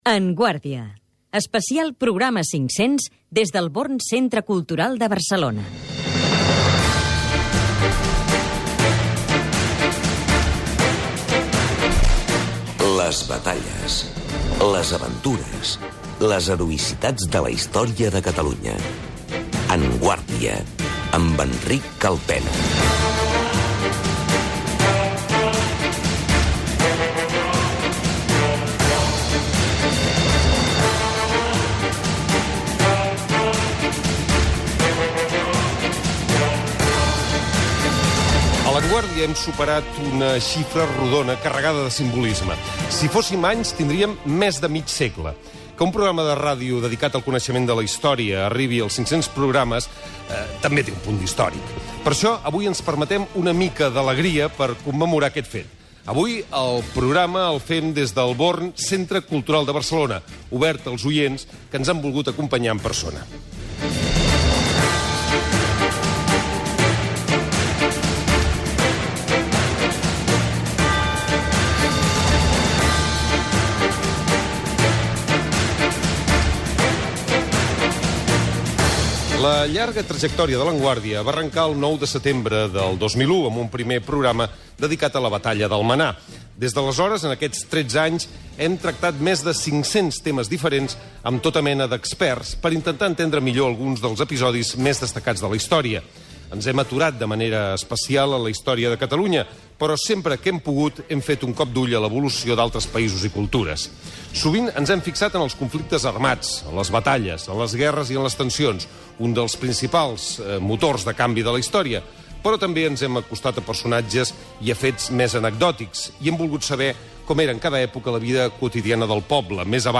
Descripció Indicatiu especial programa 500, careta del programa, presentació recordant la història del programa.